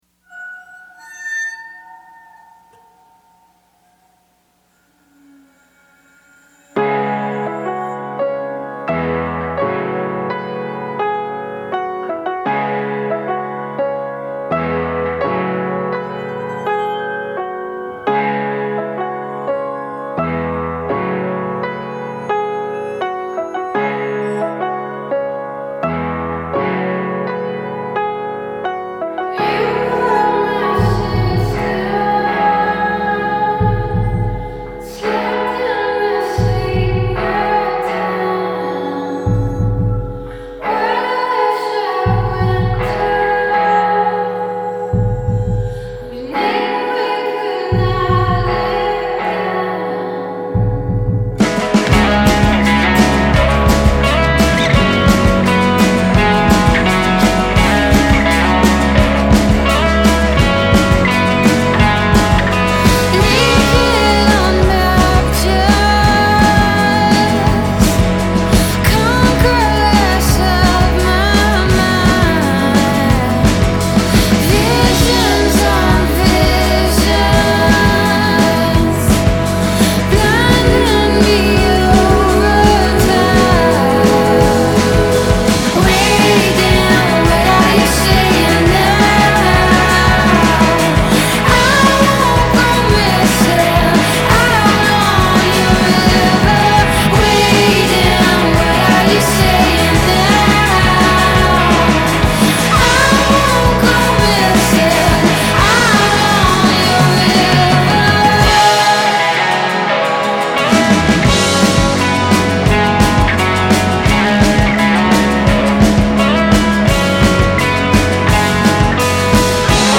a soothing lead vocalist